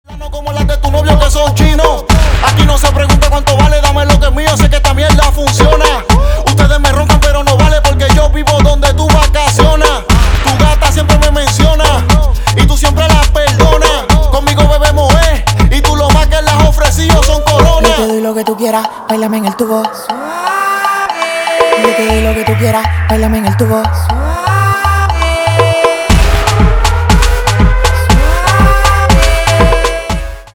поп
ремиксы
битовые , басы , качающие